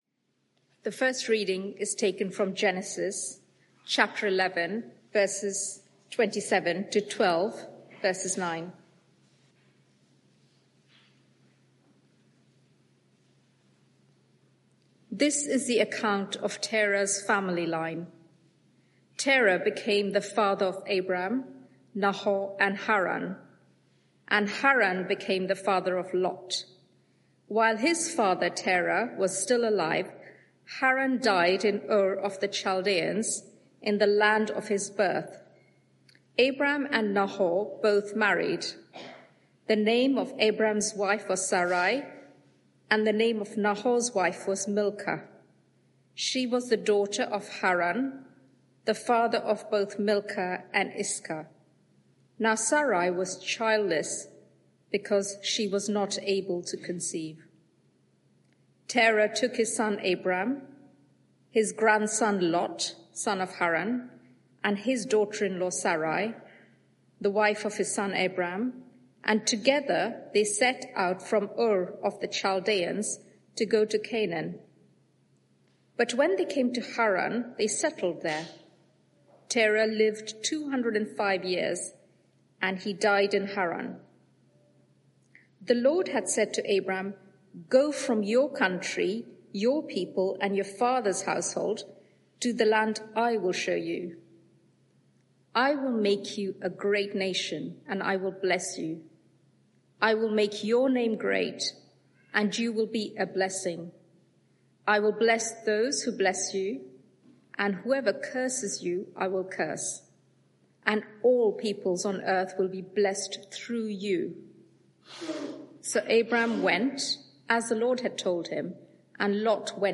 Media for 6:30pm Service on Sun 08th Dec 2024 18:30 Speaker
Sermon (audio) Search the media library There are recordings here going back several years.